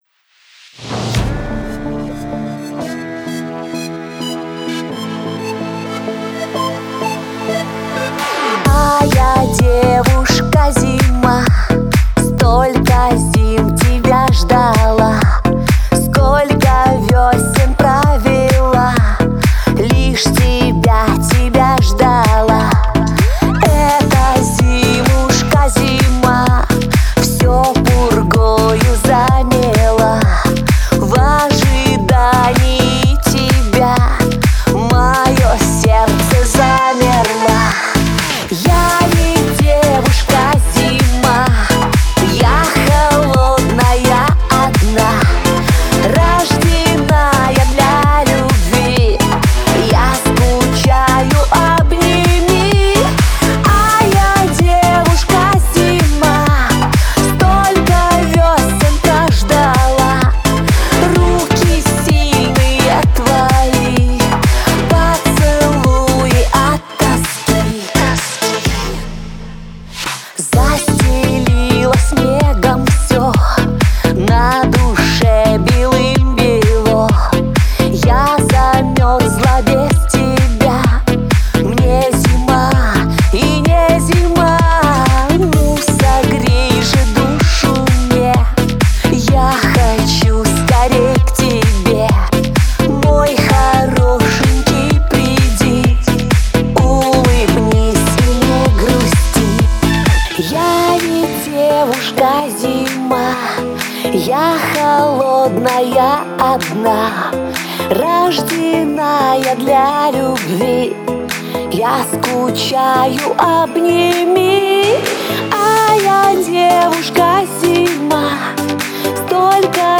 это трек в жанре поп с элементами фолка